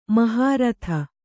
♪ mahāratha